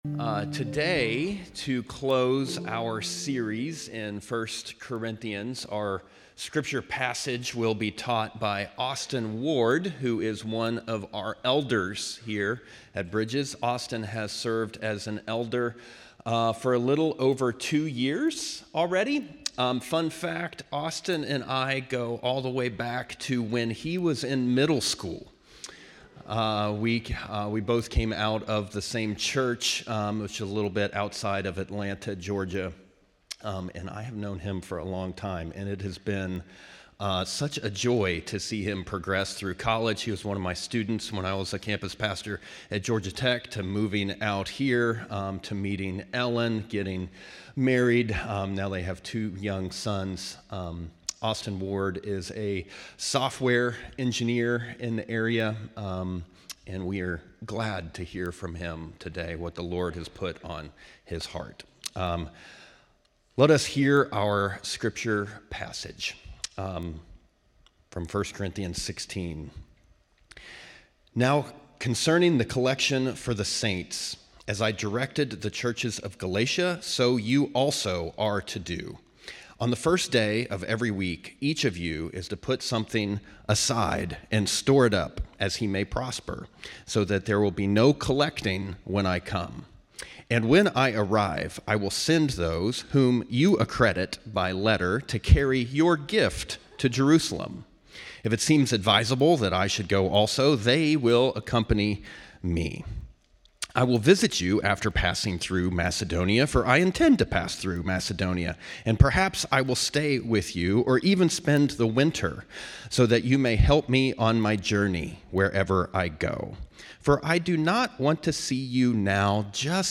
Join us each week as we dive deep into the Word of God and explore the life-transforming message of Jesus and the grace He offers us. In each episode, we bring you the dynamic and inspiring Sunday messages delivered by our passionate and knowledgeable pastors.